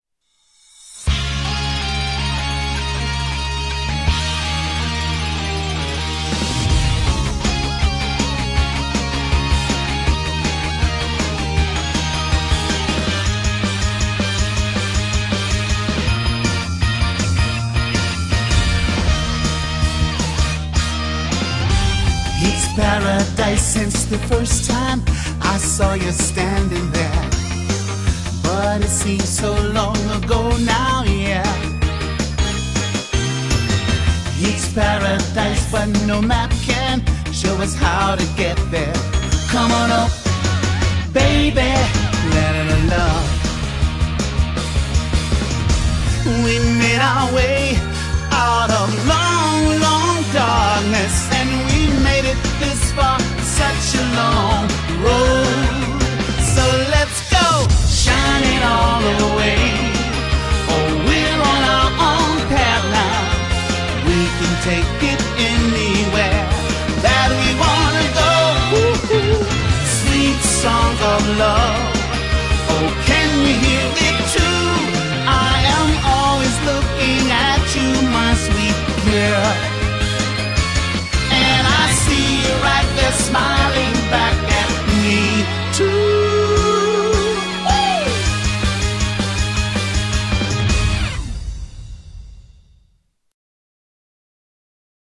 BPM160
Audio QualityPerfect (Low Quality)